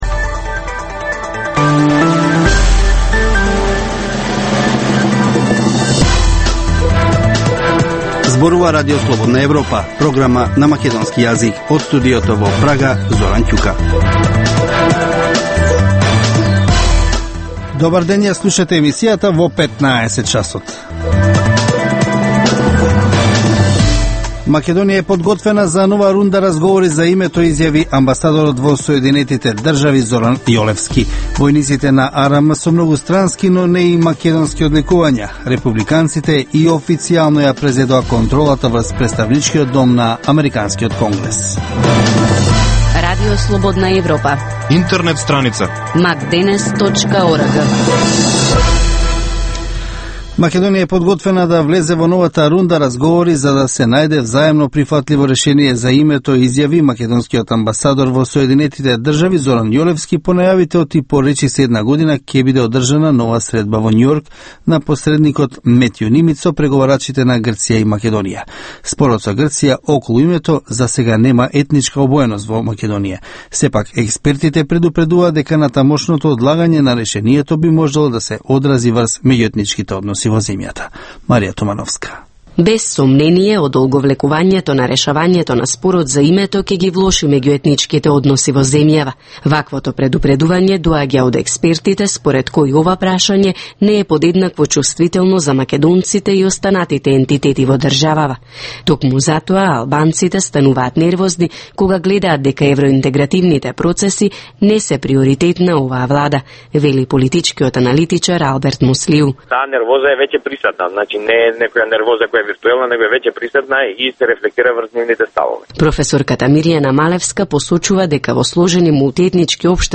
Информативна емисија, секој ден од студиото во Прага. Вести, актуелности и анализи за случувања во Македонија на Балканот и во светот.